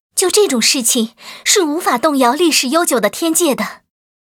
文件 文件历史 文件用途 全域文件用途 Erze_amb_08.ogg （Ogg Vorbis声音文件，长度4.2秒，98 kbps，文件大小：50 KB） 源地址:地下城与勇士游戏语音 文件历史 点击某个日期/时间查看对应时刻的文件。